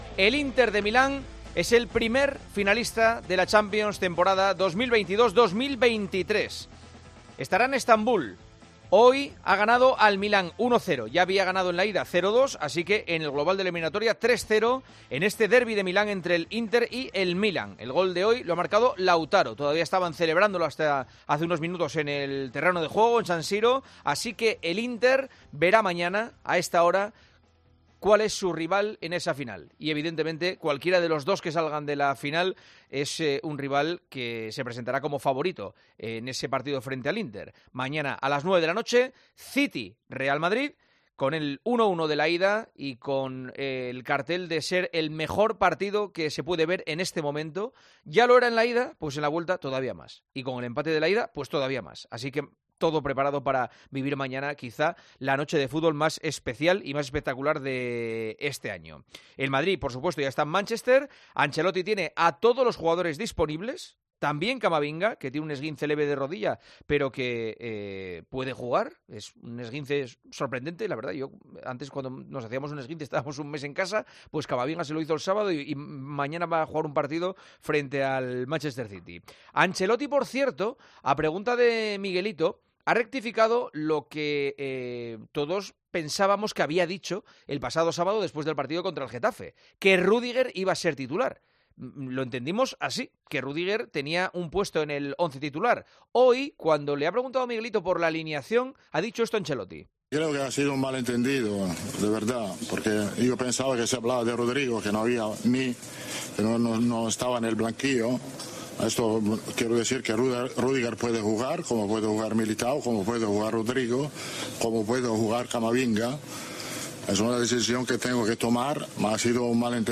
Escucha las palabras de Carlo Ancelotti y Pep Guardiola en la previa del Manchester City - Real Madrid